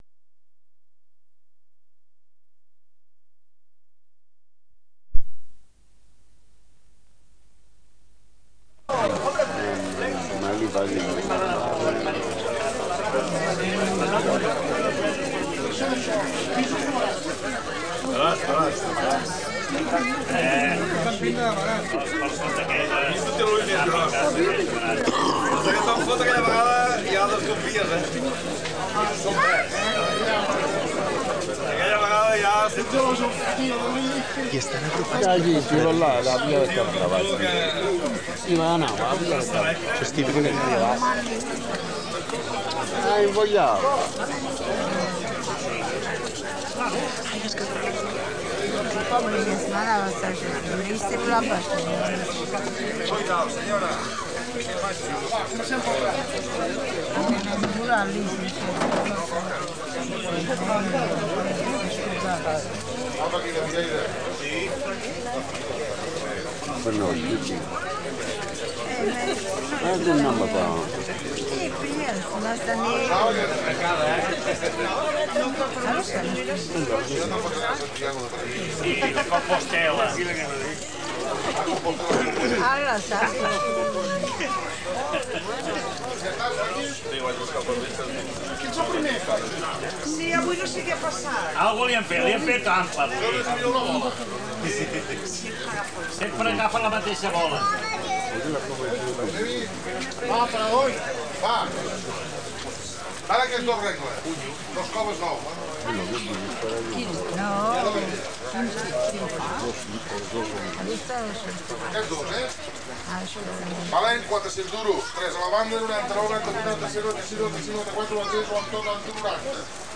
Corpus Oral de Registres (COR). EMP3. Subhasta de peix
Aquest document conté el text EMP3, una "subhasta de peix" que forma part del Corpus Oral de Registres (COR). El COR és un component del Corpus de Català Contemporani de la Universitat de Barcelona (CCCUB), un arxiu de corpus de llengua catalana oral contemporània que ha estat confegit pel grup de recerca Grup d'Estudi de la Variació (GEV) amb la finalitat de contribuir a l'estudi de la variació dialectal, social i funcional en la llengua catalana.